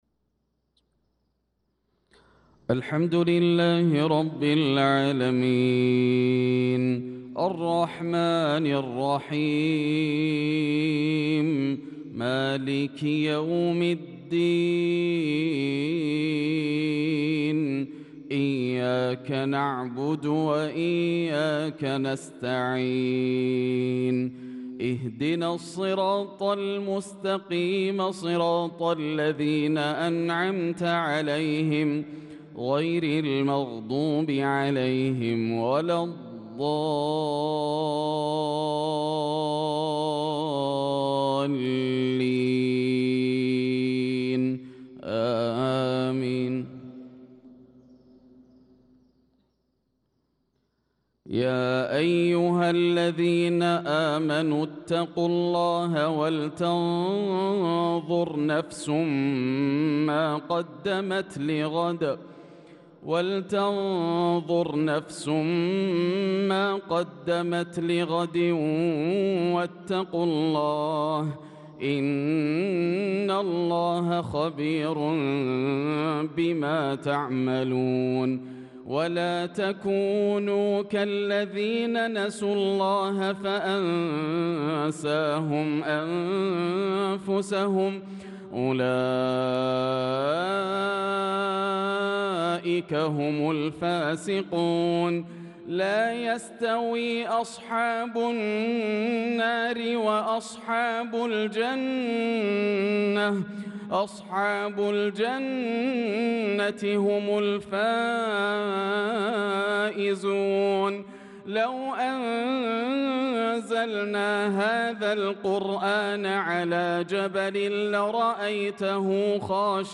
صلاة المغرب للقارئ ياسر الدوسري 16 ذو القعدة 1445 هـ